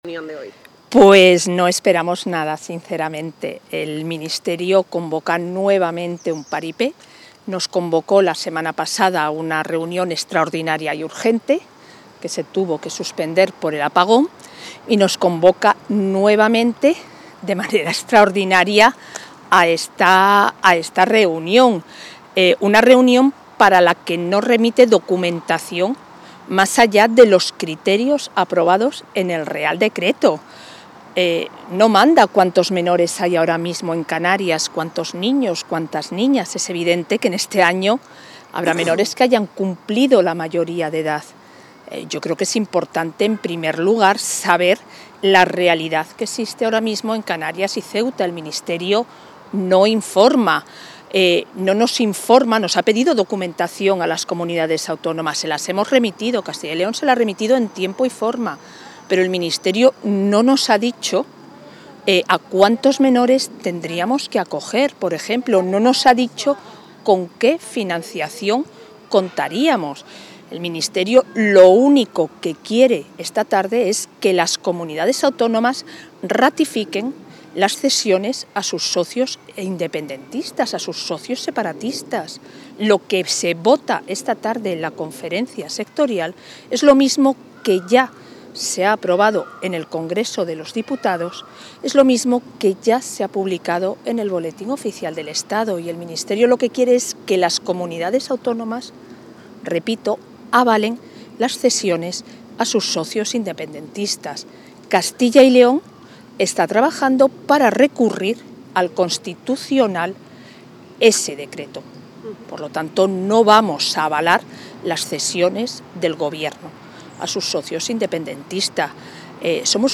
Declaraciones de la vicepresidenta de la Junta previas a la Conferencia Sectorial de Infancia y Adolescencia